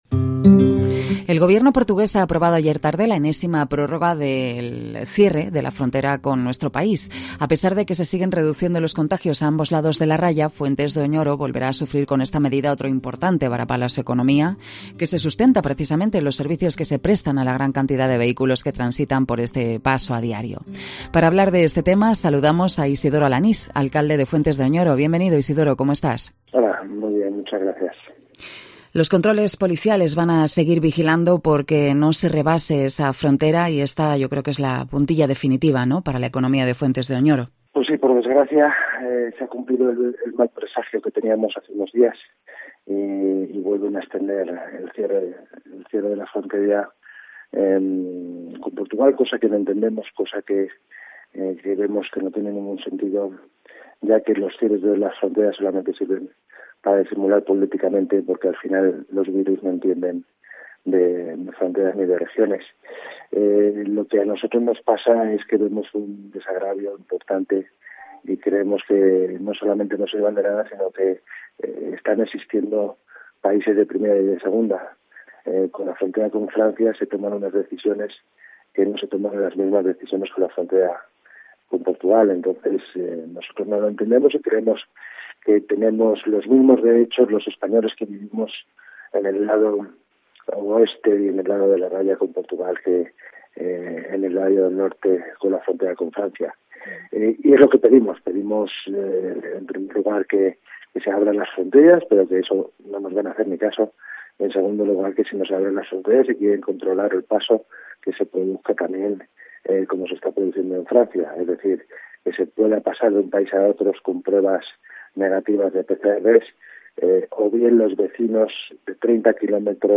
COPE Salamanca entrevista al alcalde de fuentes de Oñoro Isidoro Alanís